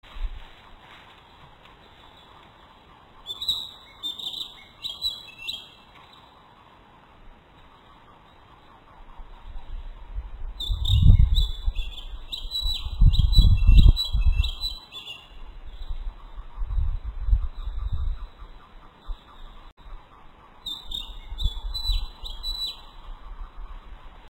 キビタキの囀り
kibitaki01.mp3